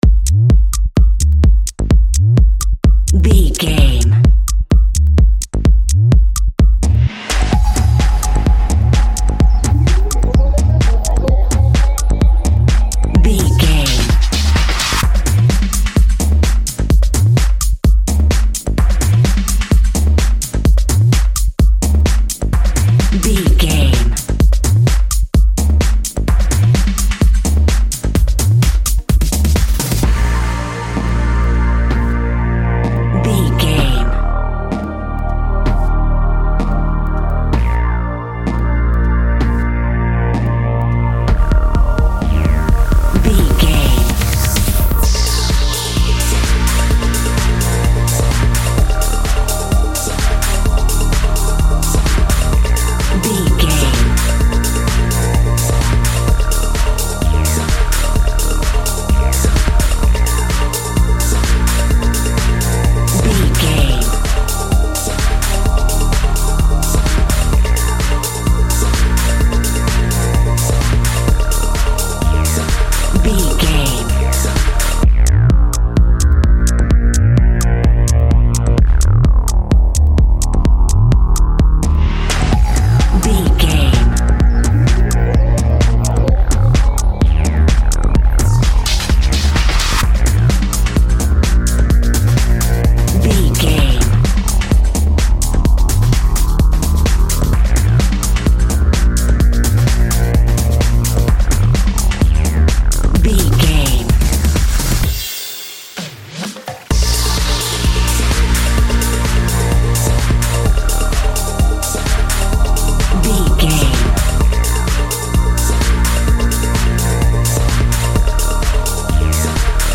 Chilled Light Club Music.
Ionian/Major
B♭
Fast
groovy
uplifting
driving
energetic
repetitive
drums
drum machine
synthesiser
house
electro dance
techno
trance
synth leads
synth bass
uptempo